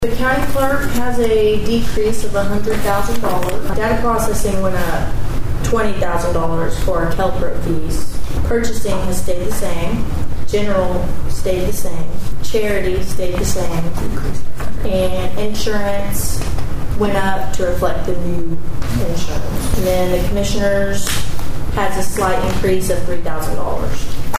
At Monday's Osage County Commissioners meeting, the Board continued to review the budgets for county offices for the 2024-2025 fiscal year.